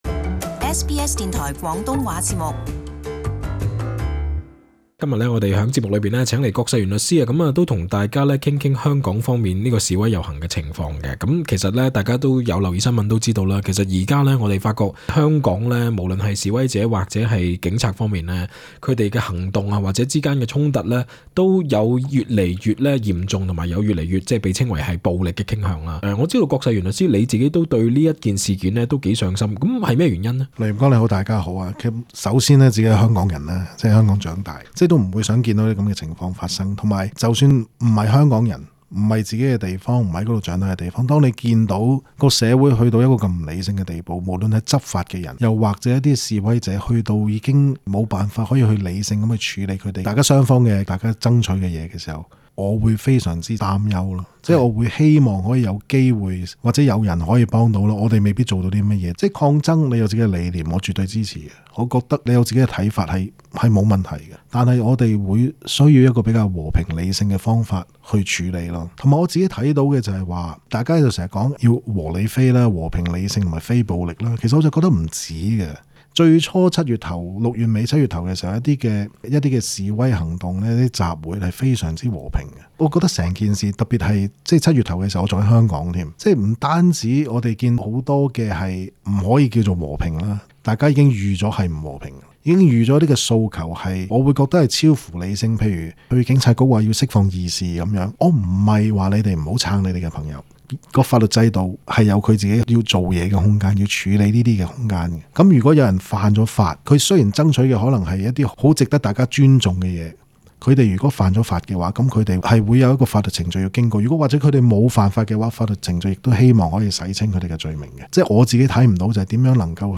【特別專訪】